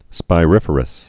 (spī-rĭfər-əs)